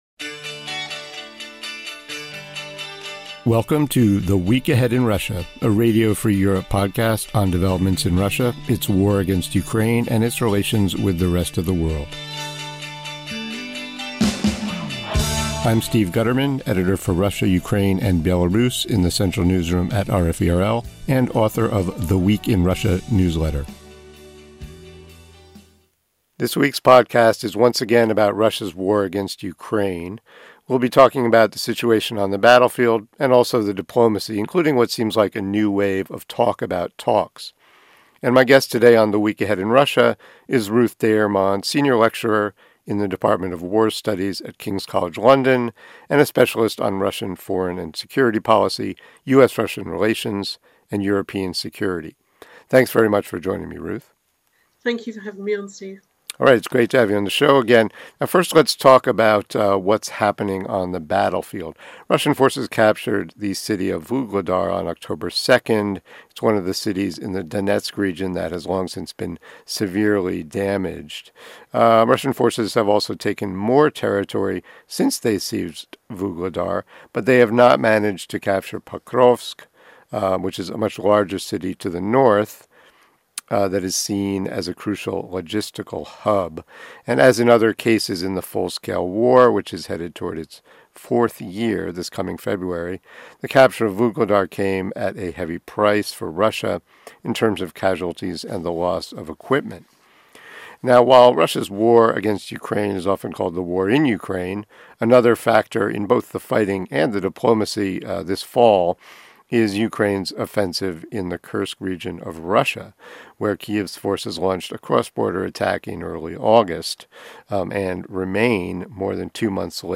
joins host